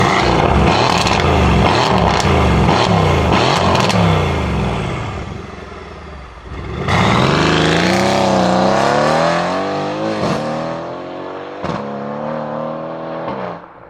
Звуки гоночного автомобиля